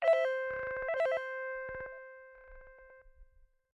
call_waiting.wav